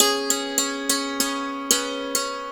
SANTOOR2  -L.wav